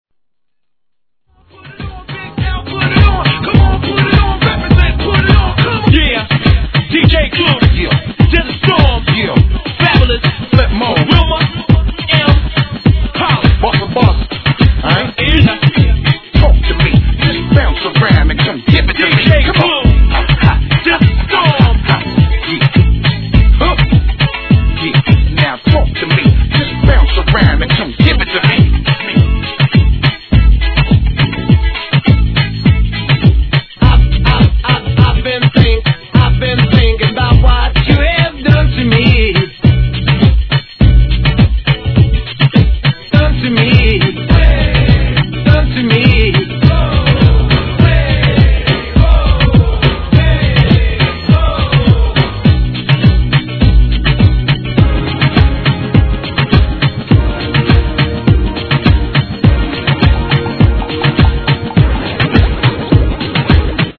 HIP HOP/R&B
さらに次々と一度は耳にしたことがあるであろう、様々なアーティストのキャッチーなフレーズが飛び出すPARTY物!!